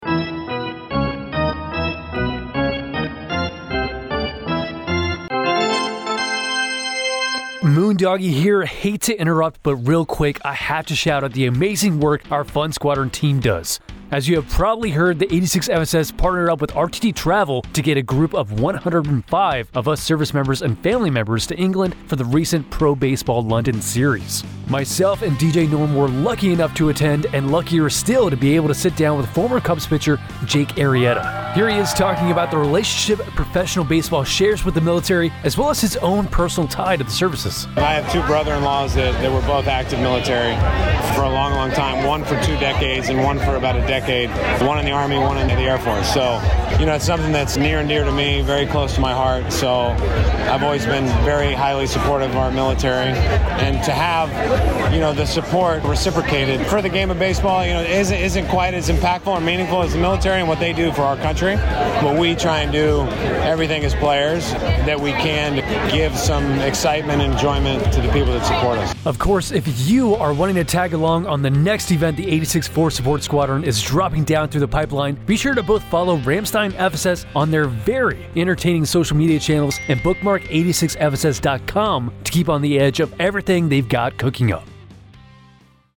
Radio Spot - MLB trip
This is a 1-minute, 30-second radio talk segment detailing the work done by the 86th Force Support Squadron and RTT Travel to bring 105 service members and family members to London for a professional baseball game series.